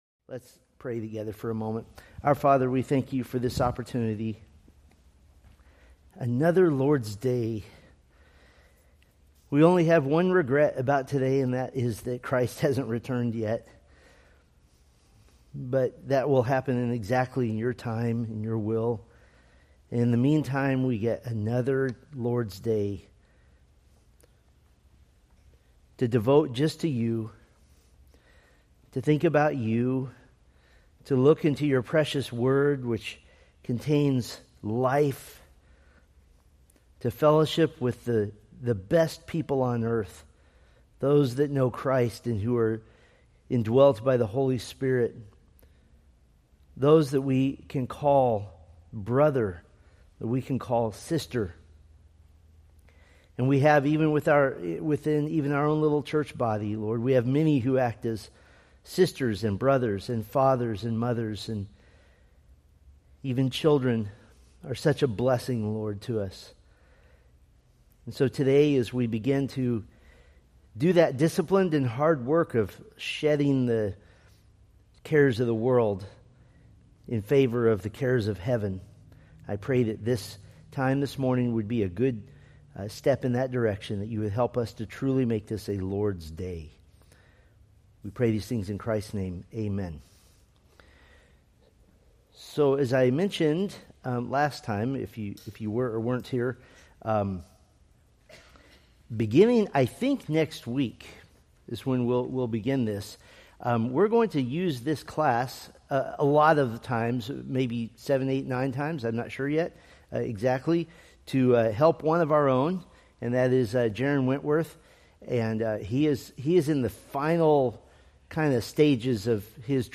Date: Aug 17, 2025 Series: Psalms Grouping: Sunday School (Adult) More: Download MP3 | YouTube